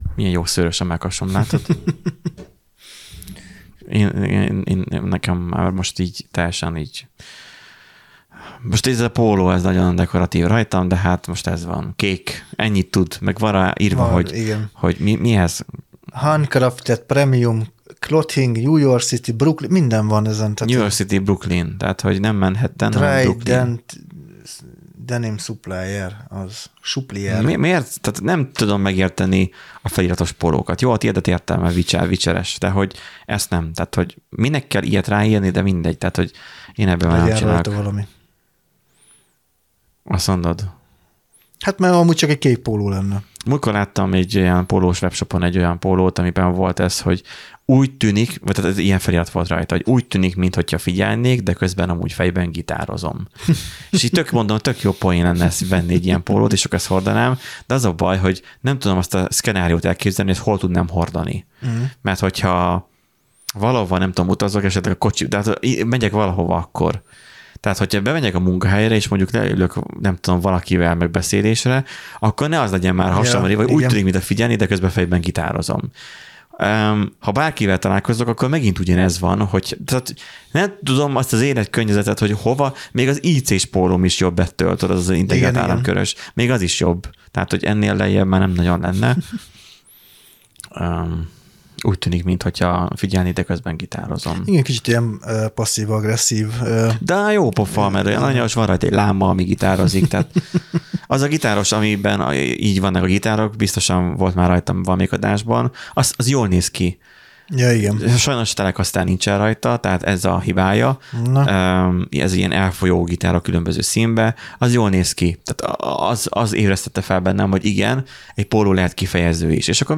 Ez egy 2019. óta heti rendszerességgel jelentkező tech és közéleti podcast. Néhány borsodi programozó srác leül hétről hétre és elmondják véleményüket a világban történtekről.